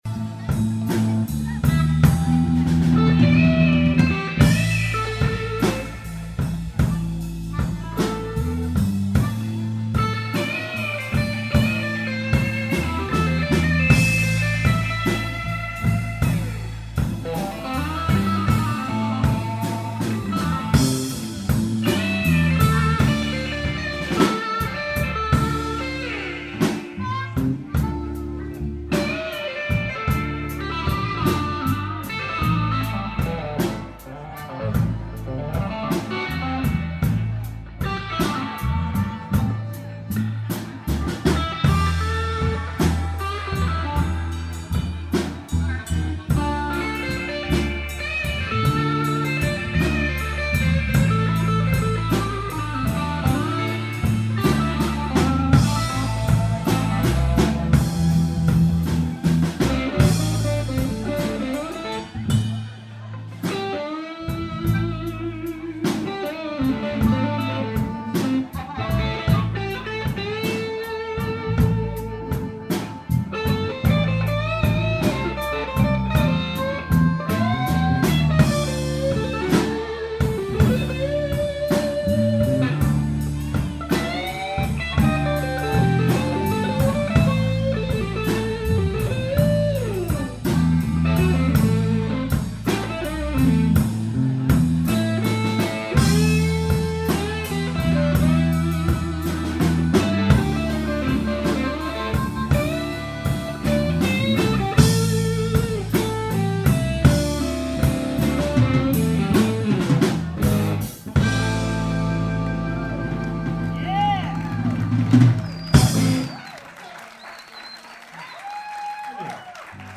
La Peter Green mod :... qui donne un son très caractérique "out of phase", assez difficile à décrire par des mots mais tout de suite reconnaissable.
Le son de la gratte est superbe aussi...
TR3-LTBsolo.mp3